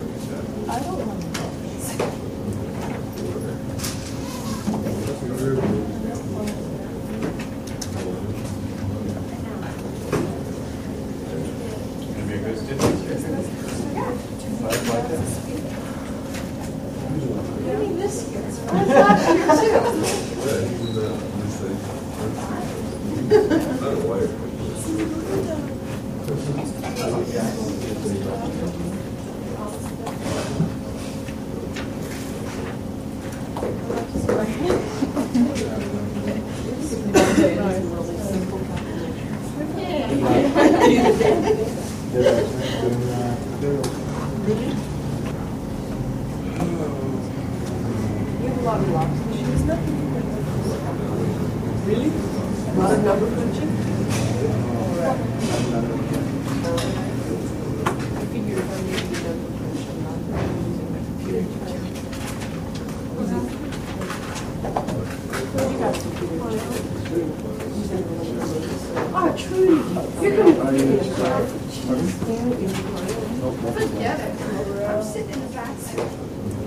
Student Walla
Classroom Light Walla, Looped